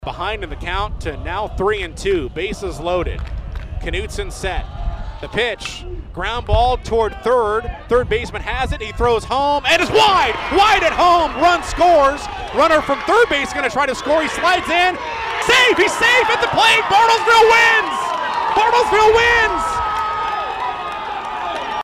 Final Call Bruins Win v Union 5-1(1).mp3